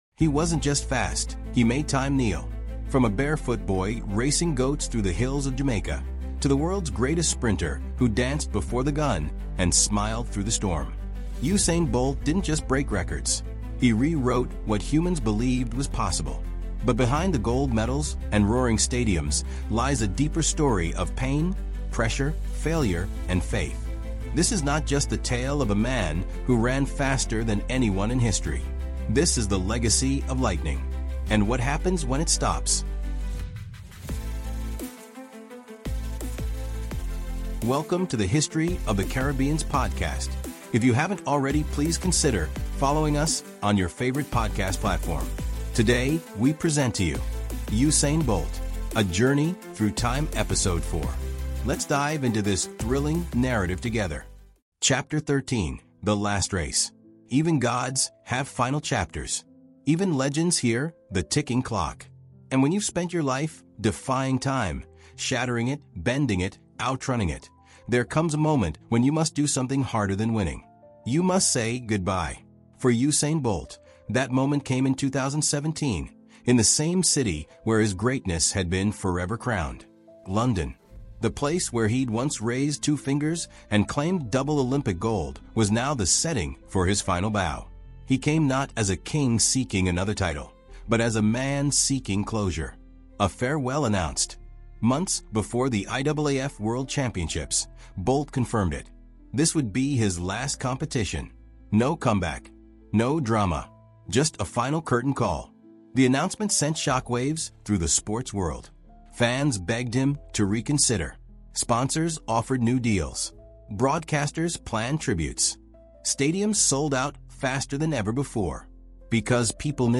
From his humble beginnings on the dusty roads of Sherwood Content, Jamaica, to the roaring stadiums of Beijing, London, and Rio, Usain Bolt: The Legacy of Lightning is the definitive 16-chapter storytelling audiobook chronicling the rise, reign, and remarkable afterlife of the fastest man in history.
With immersive narration, cinematic pacing, and emotional depth, this series explores the triumphs, injuries, doubts, and dreams that shaped his journey.